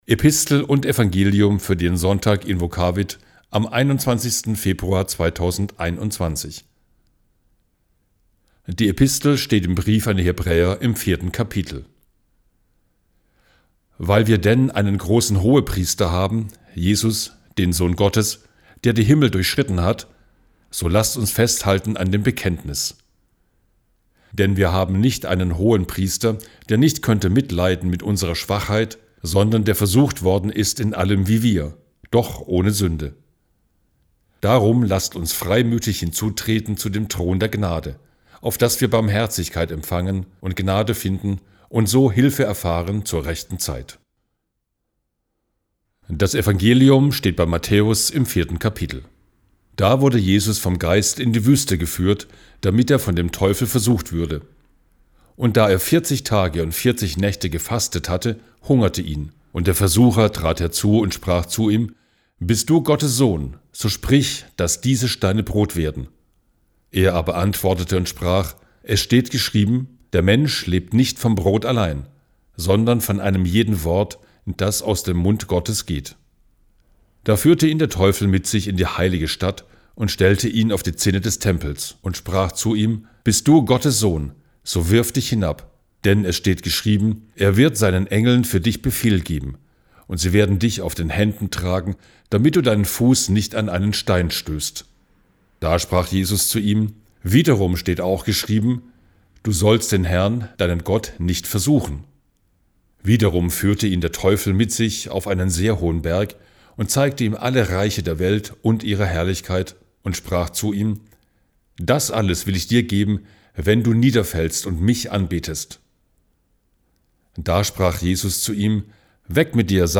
Gottesdienst 21.02.2021 | Ev.-luth.